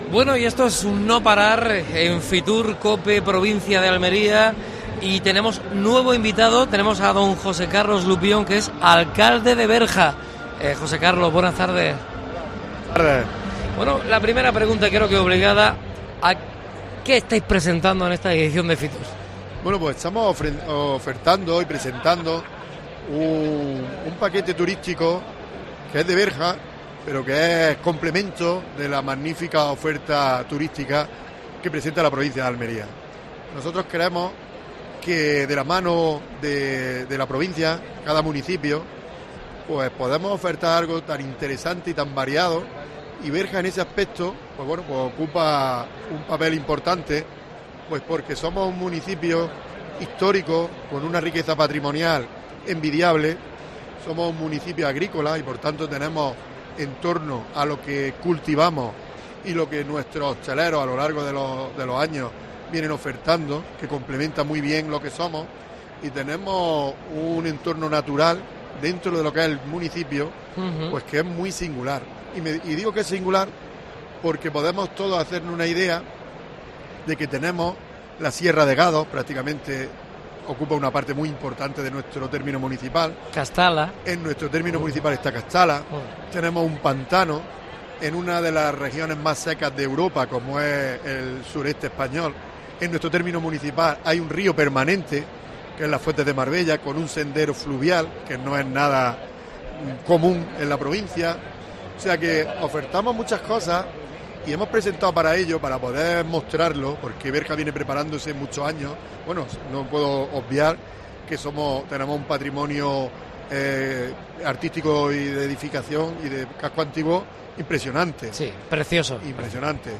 AUDIO: Especial FITUR en COPE Almería. Entrevista a José Carlos Lupión (alcalde de Berja).